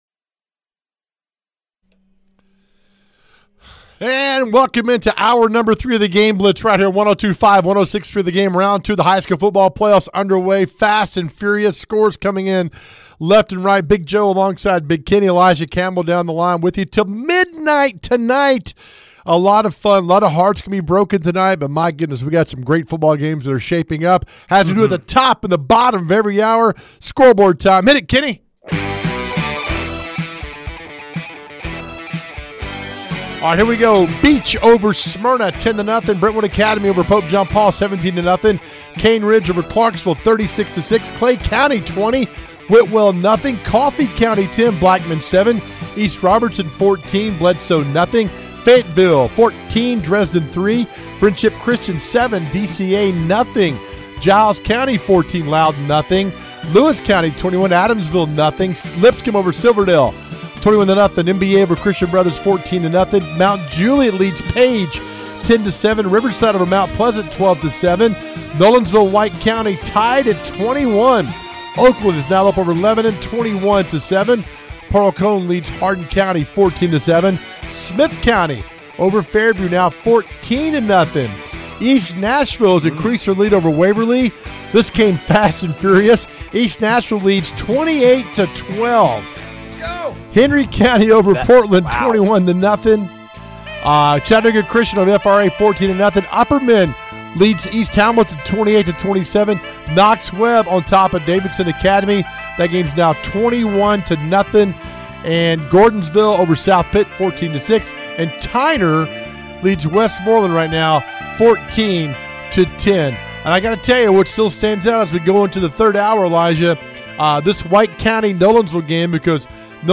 We are in round two of TN High School Football and we cover it all with head coach and reporter interviews!